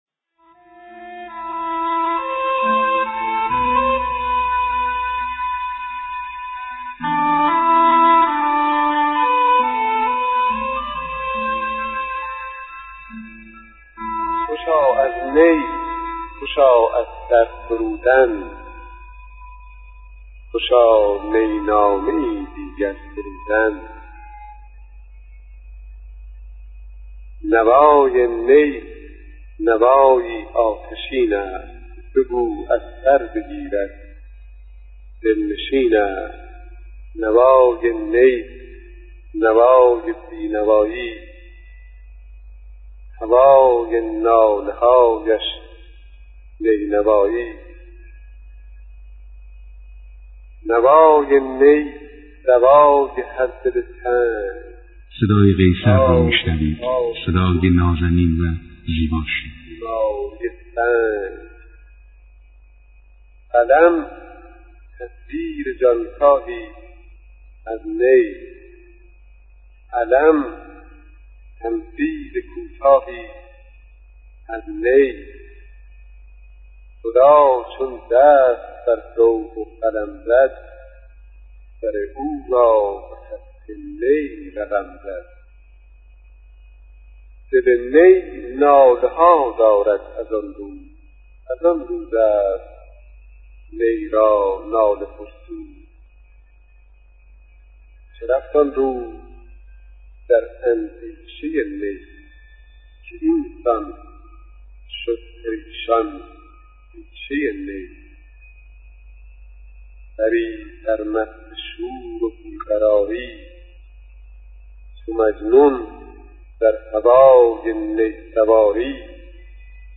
مثنوی نی نامه با صدای قیصر امین پور
شعر خوانی, شعر معاصر, صدا, قالبهای شعری, قیصر امین پور, مثنوی